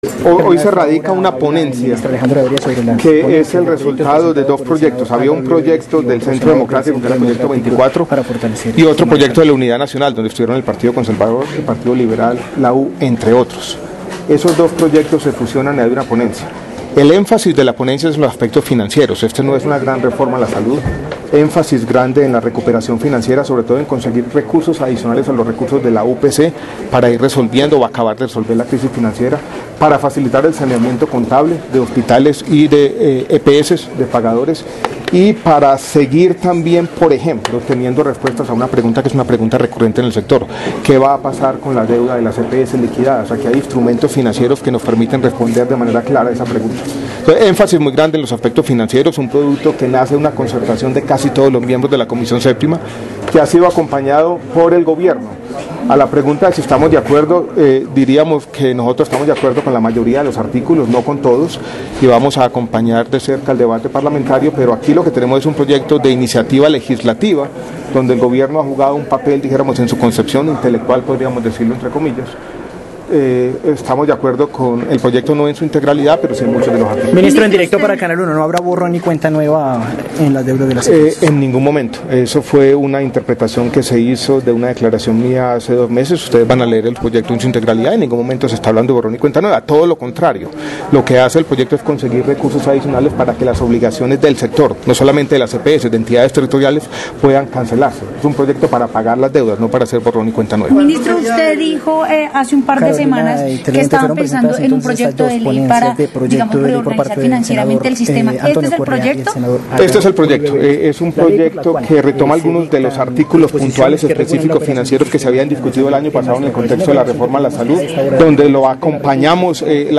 El Ministro de Salud y Protección Social, Alejandro Gaviria Uribe
Audio: declaraciones MinSalud, flujo de recurso del sistema de salud